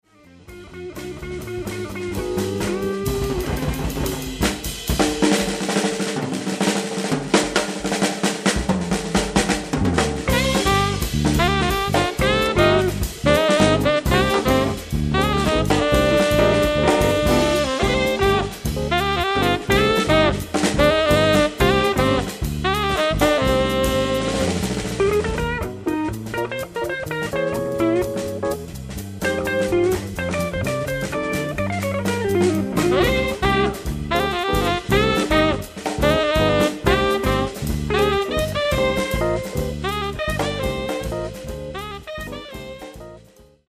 Guitar
Piano
Sax
Drums